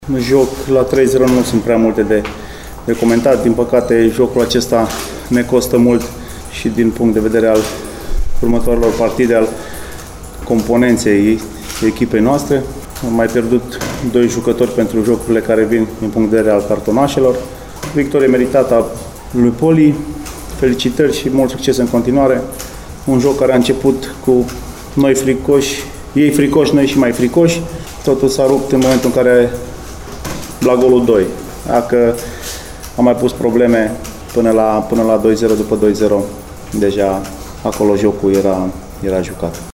În fişierele alăturate puteţi asculta reacţiile celor doi tehinicieni